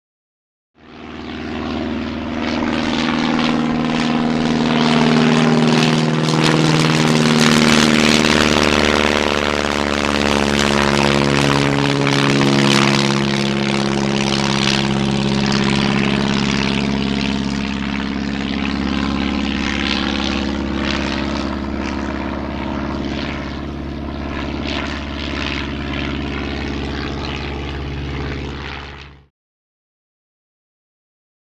Prop Plane; Take Off; Fokker Single Engine Prop Aircraft Circa 1914 Take Off, Then Fly's Around Some Good Fly Up And Overheads, Eventually Lands In Long Shot, Taxis Up And Past And Switches Off In L